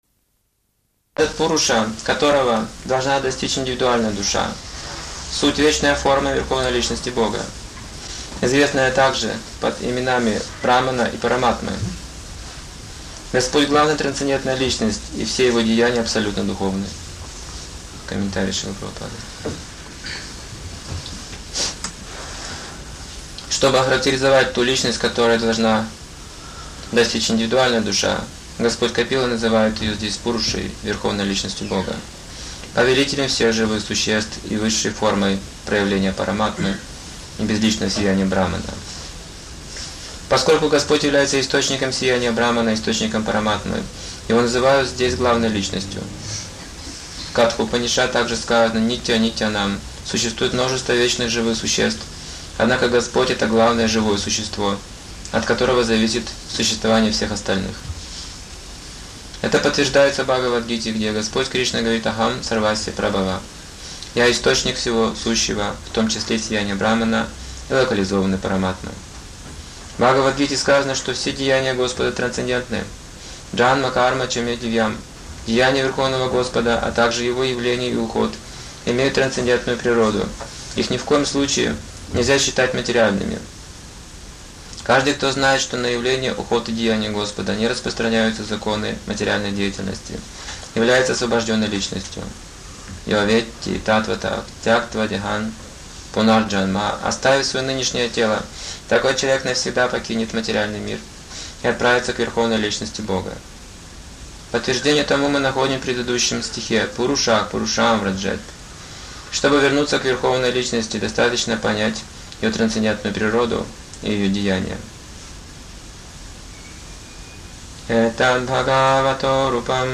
Лекции